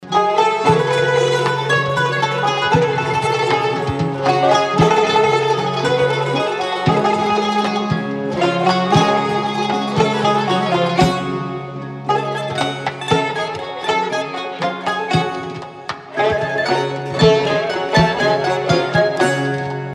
رینگتون زیبای عاشقانه با موسیقی آذری(بی کلام)